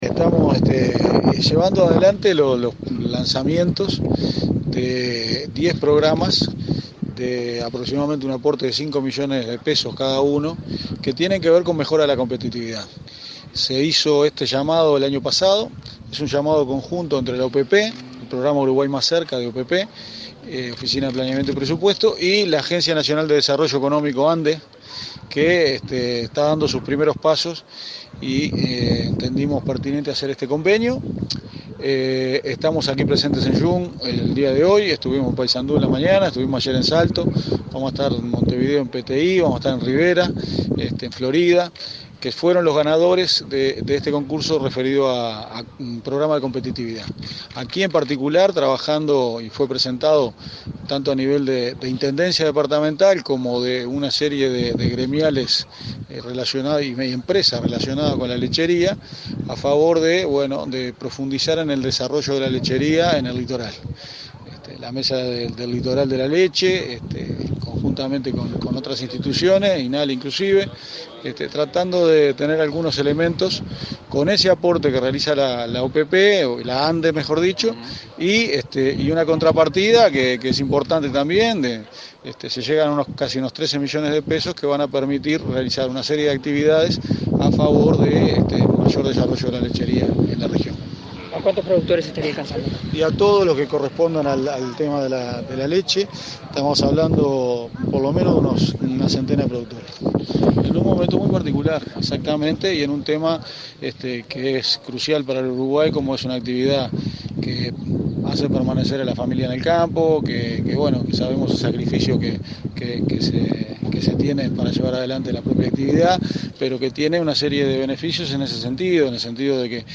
La Oficina de Planeamiento y Presupuesto realiza esta semana el lanzamiento de diez proyectos de competitividad territorial, relacionados con el desarrollo de capacidades productivas sustentables de la lechería del litoral norte. El director de OPP, Álvaro García, dijo a la prensa, este martes en Young, que se invertirán unos 5 millones de pesos por cada uno de estos proyectos que abarcan en total a unos cien productores.